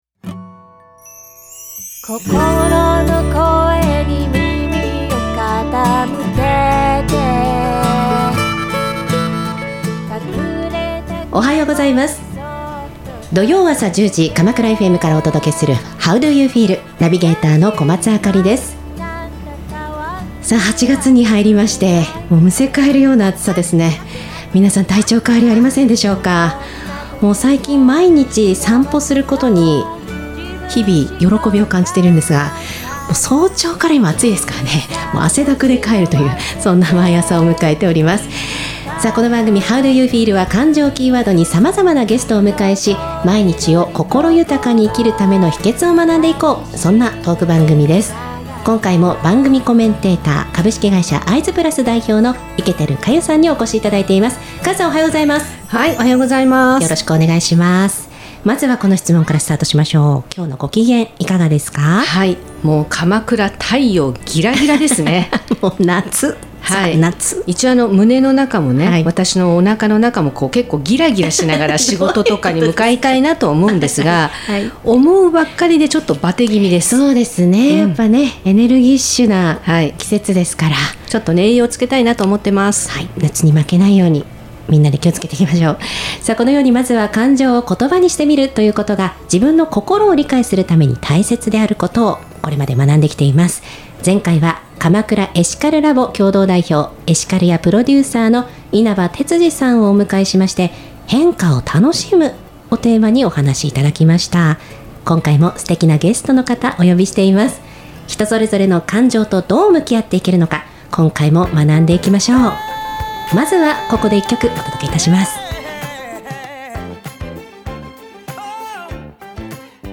番組では「感情知性=EQ」に注目!自身の感情を見つめ、心を豊かにするヒントを学んでいく、ゲストトーク番組です。